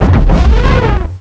pokeemerald / sound / direct_sound_samples / cries / carracosta.aif